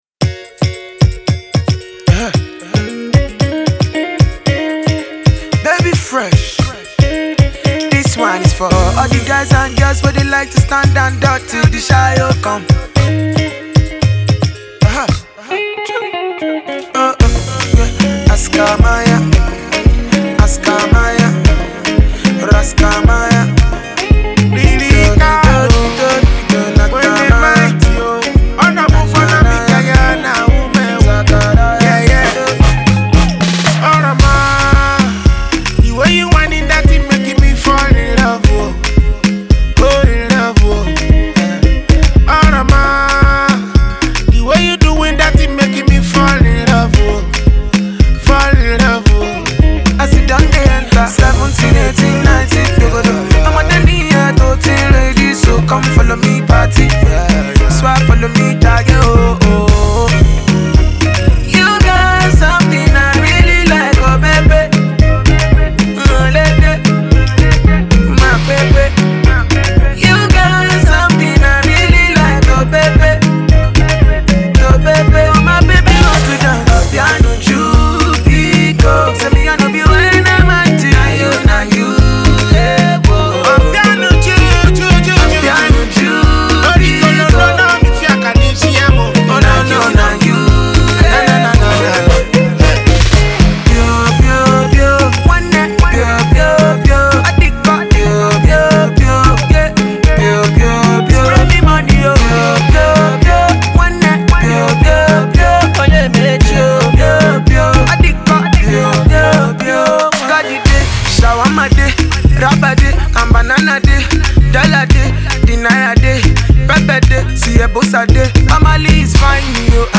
dancehall song